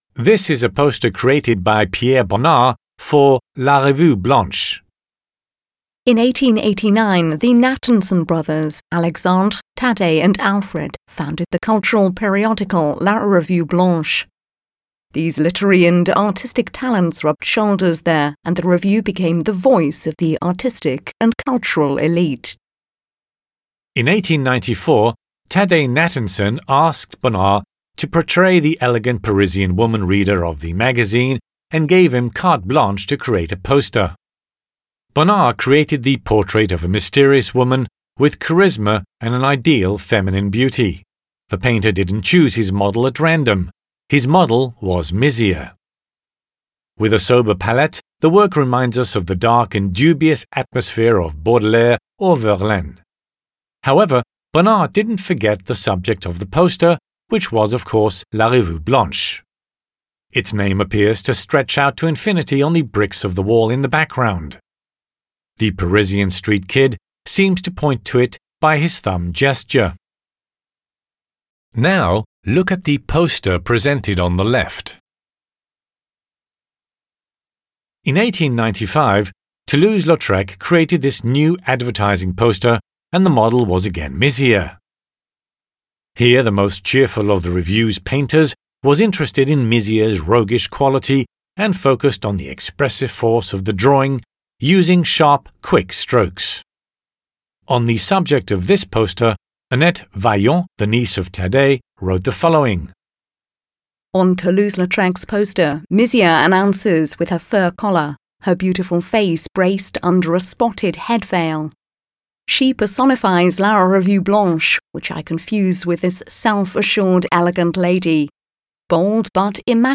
Les audioguides de la Collection